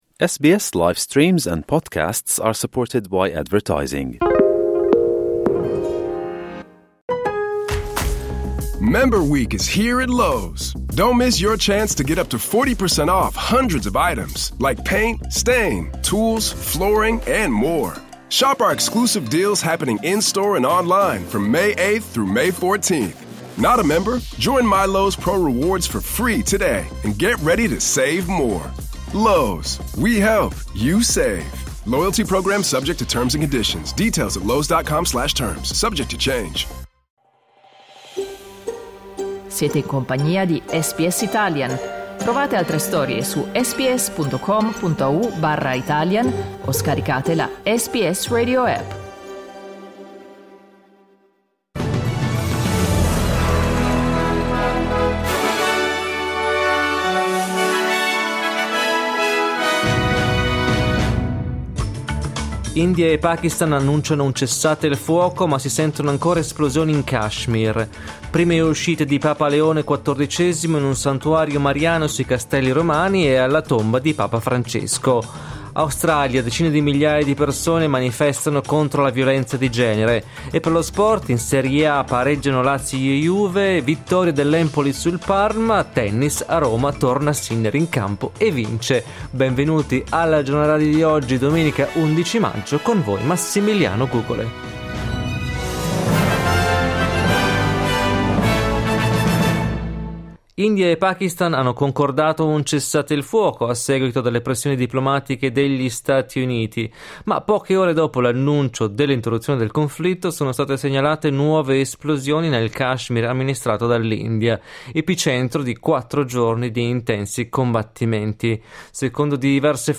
Giornale radio domenica 11 maggio 2025
Il notiziario di SBS in italiano.